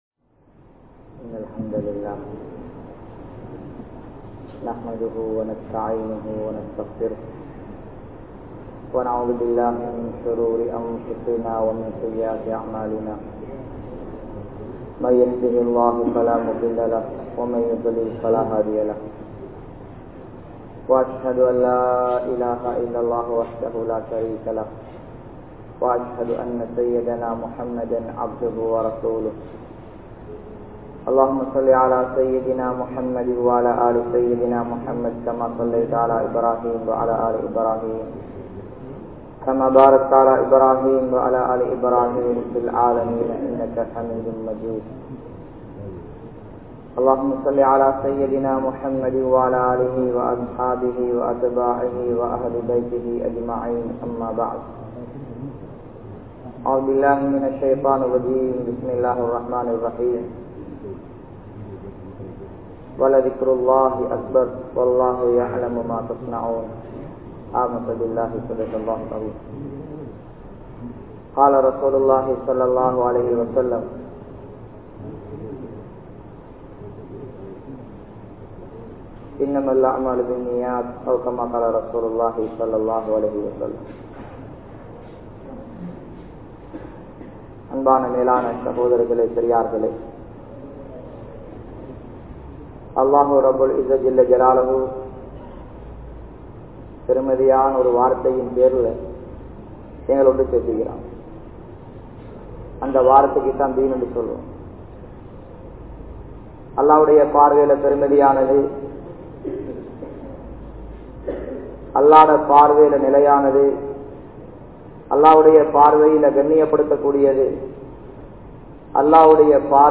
Dhauvathin Noakkam (தஃவத்தின் நோக்கம்) | Audio Bayans | All Ceylon Muslim Youth Community | Addalaichenai